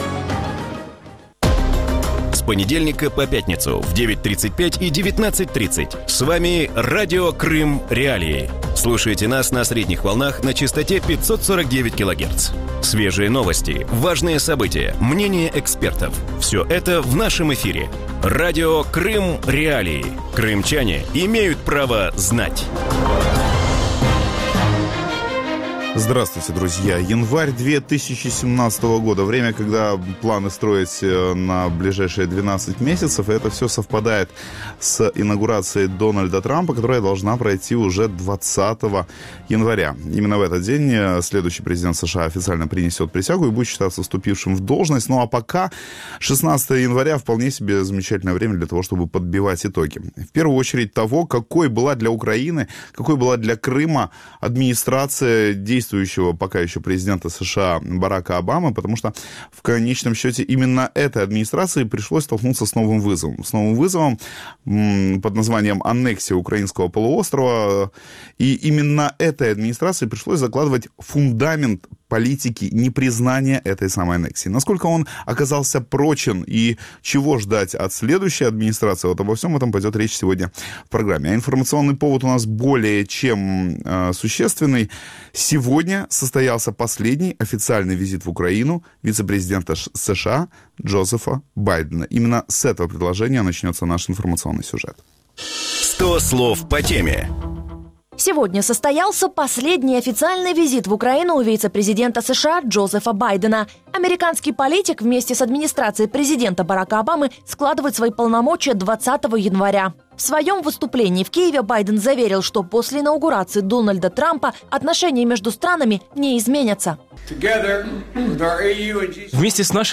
В вечернем эфире Радио Крым.Реалии говорят об итогах правления Барака Обамы на посту президента США. Стал ли Барак Обама успешным американским президентом, какие успехи и провалы были у администрации Обамы во внешней политике и какое политическое наследие получит Дональд Трамп после инаугурации?